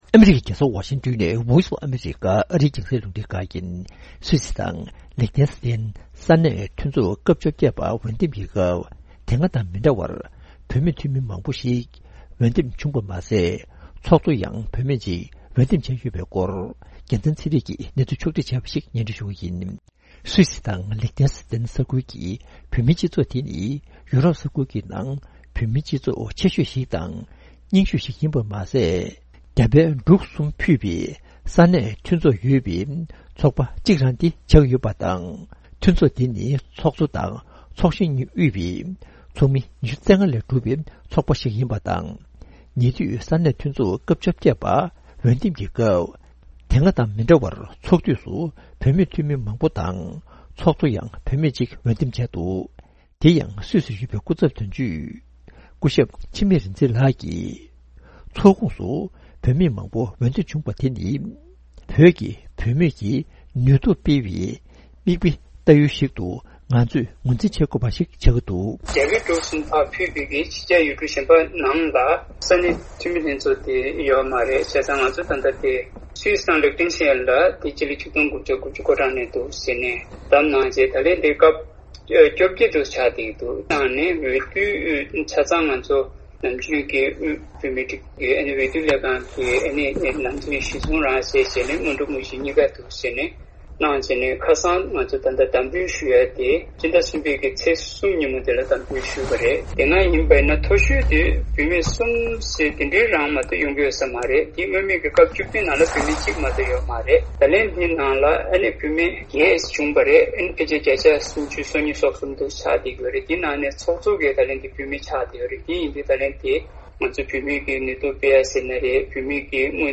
འབྲེལ་ཡོད་མི་སྣར་བཅར་འདྲི་ཞུས་ནས་གནས་ཚུལ་ཕྱོགས་བསྒྲིགས་བྱས་པ་ཞིག་སྙོན་སྒྲོན་ཞུ་རྒྱུ་རེད།།